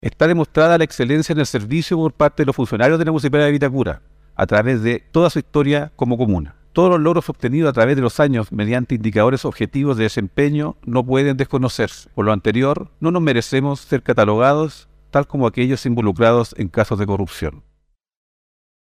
Ante el concejo municipal